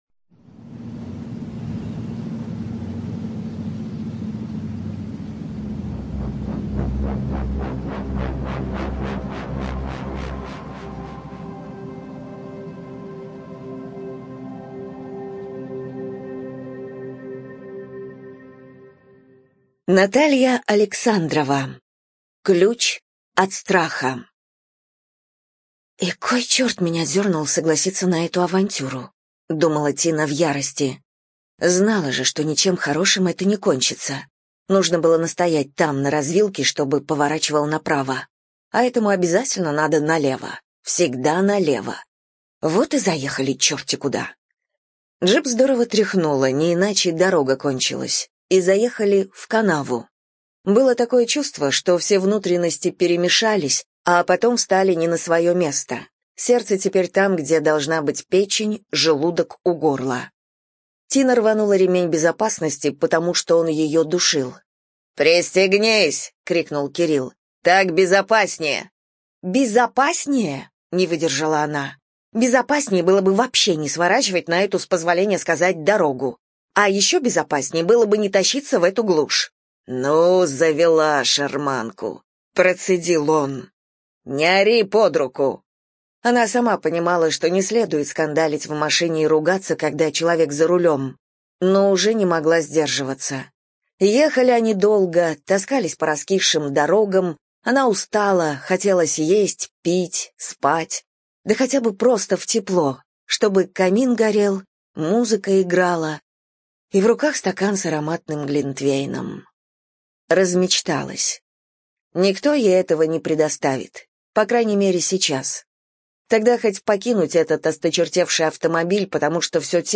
Издательство: Аудиокнига (АСТ)